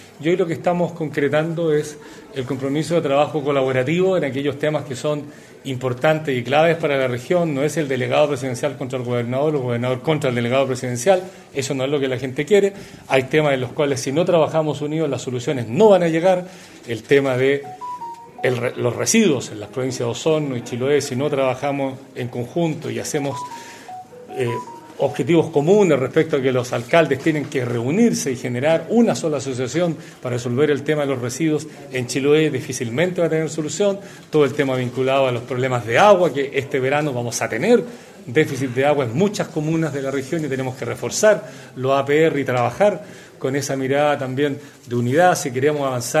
Gobernador Vallespín y Delegado Geisse encabezan reunión de gabinete con seremis El Gobernador Regional, Patricio Vallespín, indicó que se está concretando el compromiso de trabajo colaborativo de aquellos temas que son importantes y claves para la región.